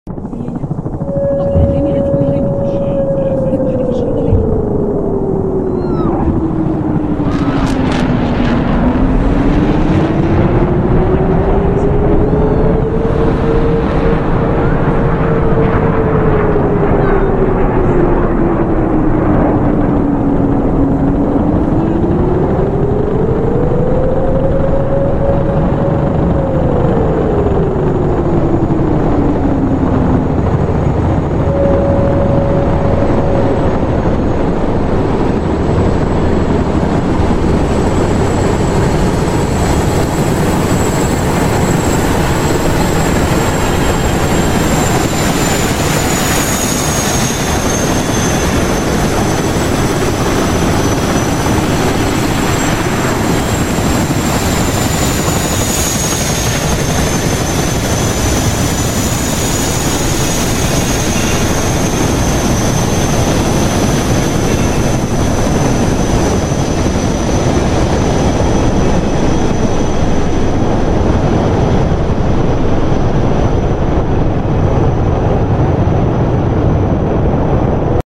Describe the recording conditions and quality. Filmed with Digital Combat Simulator